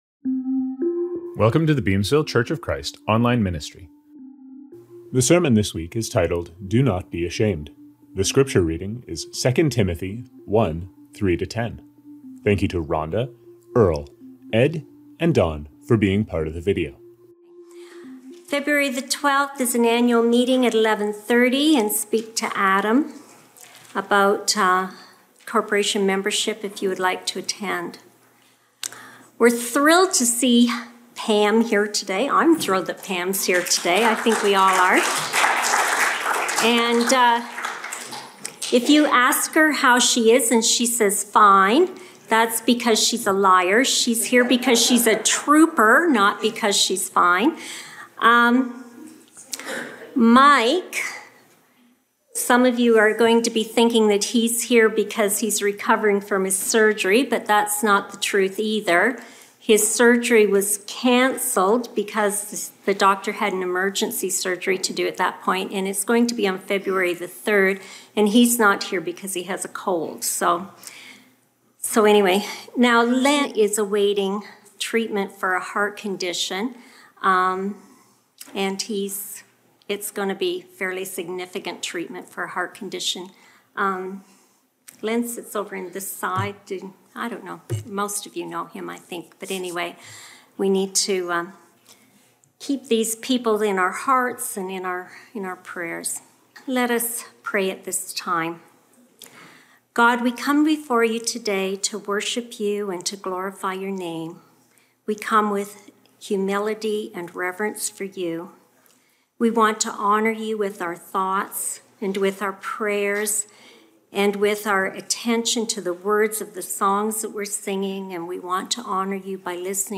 Scriptures from this service: Communion – Luke 22:14-15; Deuteronomy 31:1-3; 31:5-6; Luke 22:15-20; 22:22-23; 22:24; 22:31-34; Matthew 27:45-46; Deuteronomy 31:6. Reading: 2 Timothy 1:3-10.